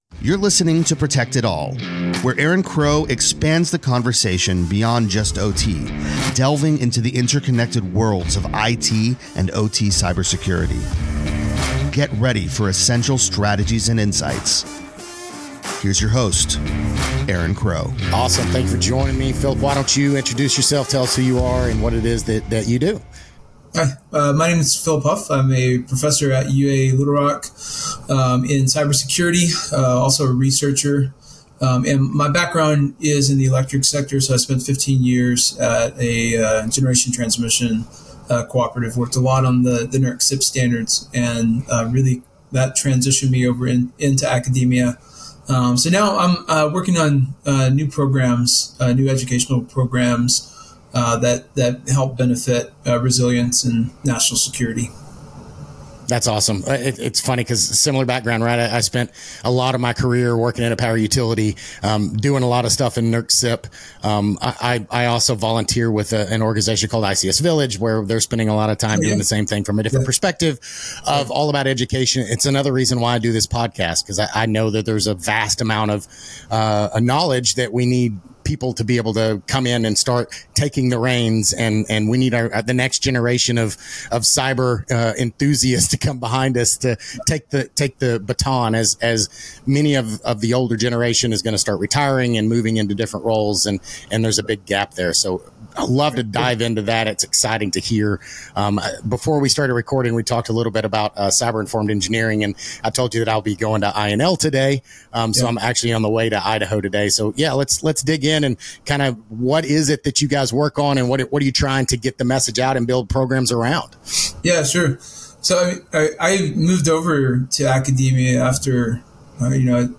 The conversation also covers the advantages of competency-based education and flexible training programs in enhancing social mobility.